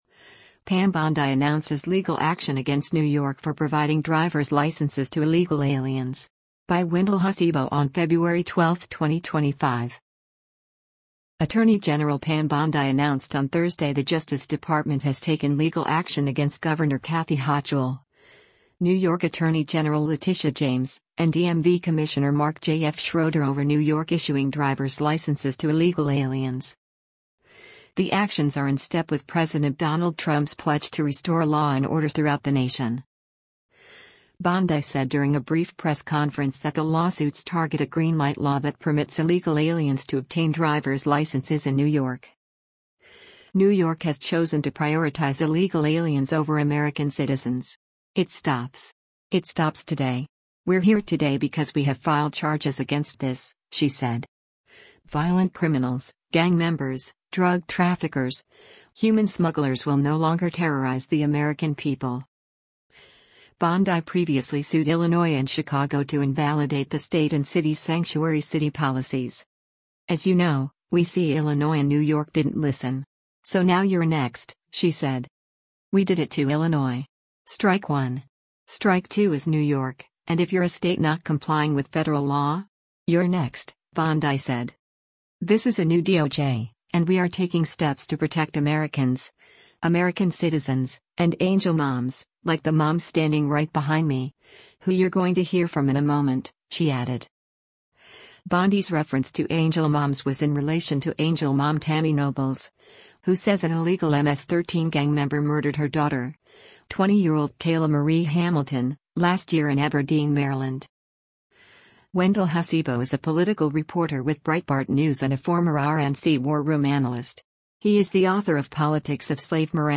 Bondi said during a brief press conference that the lawsuits target a “green light law” that permits illegal aliens to obtain driver’s licenses in New York.